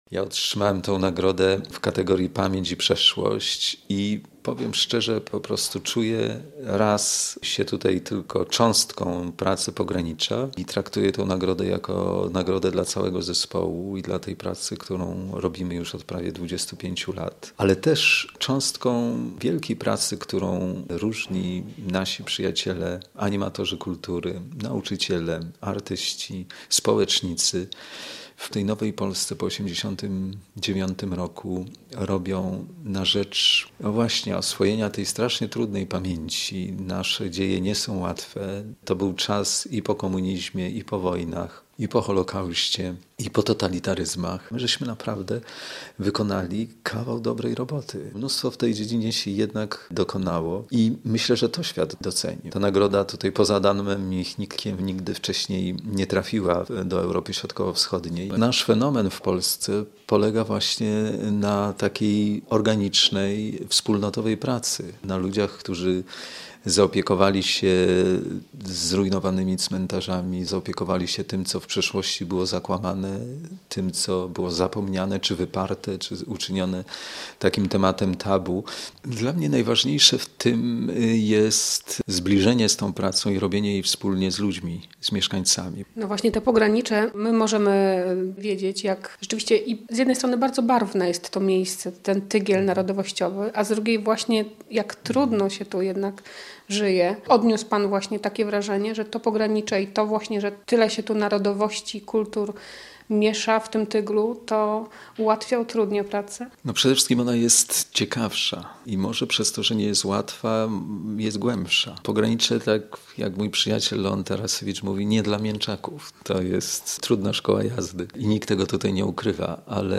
Milion dolarów dla Pogranicza - relacja